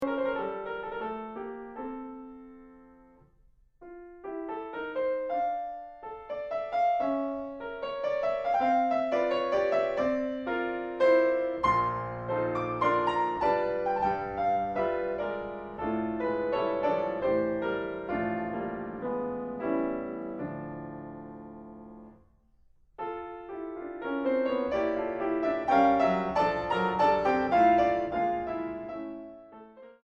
En re menor. Allegro 3.05